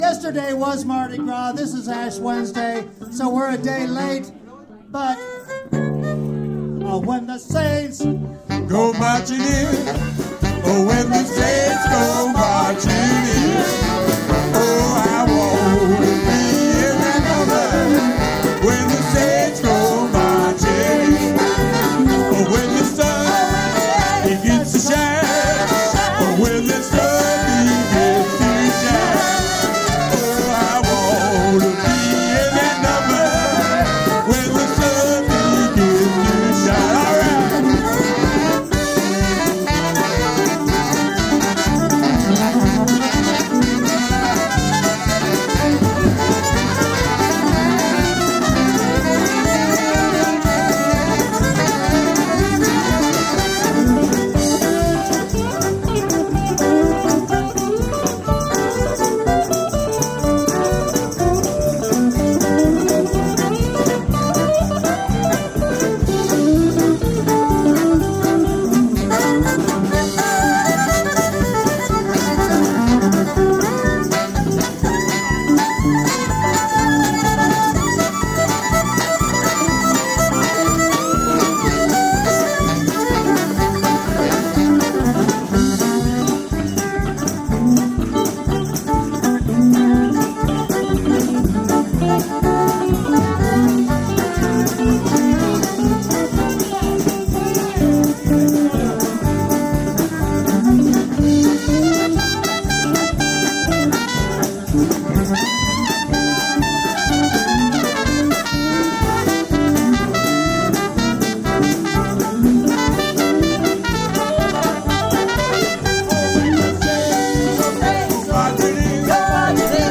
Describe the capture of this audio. - Memorial concert at Bird's - These links are to the recording of the show from my Tascam DR-07 that was mounted right in front of the monitor.